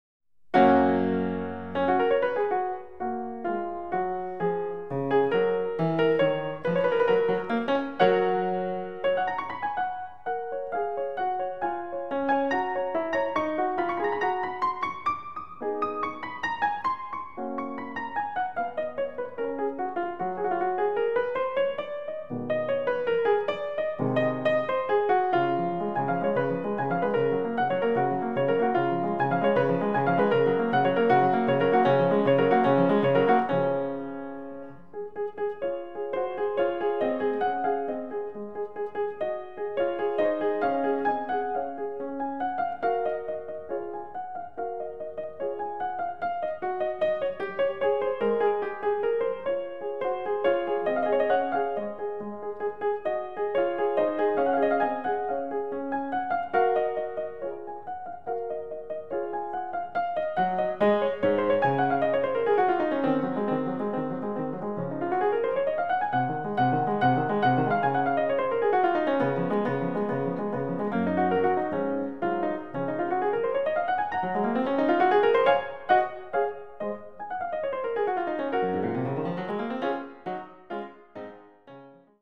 sonates
fortepiano